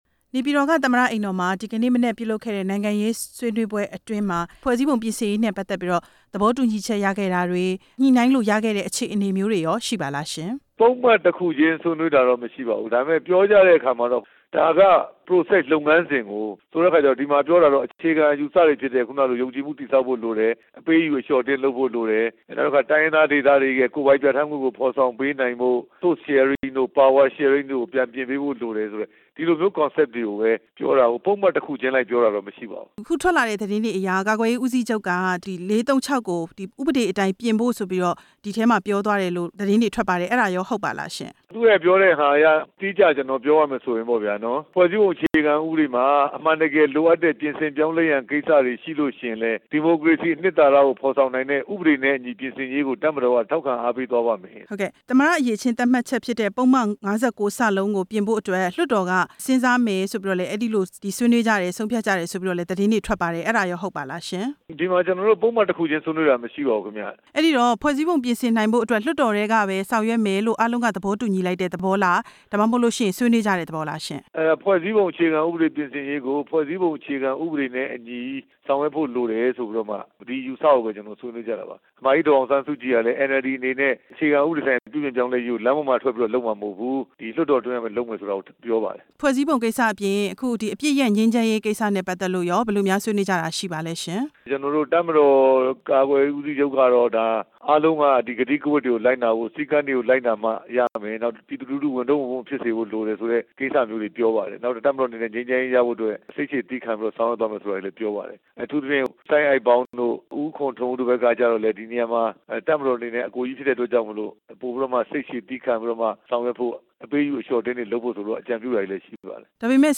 ပြန်ကြားရေးဝန်ကြီး ဦးရဲထွဋ်ကို မေးမြန်းချက်